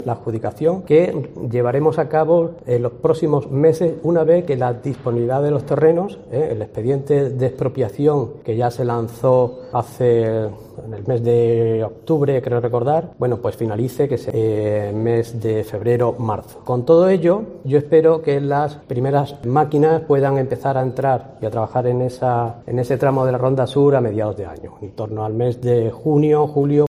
Así lo ha manifestado el consejero de Infraestructuras, Transporte y Vivienda, Manuel Martín Castizo, en declaraciones a los medios de comunicación momentos antes de reunirse este viernes con responsables del Colegio de Ingenieros, Canales y Puertos en la capital cacereña.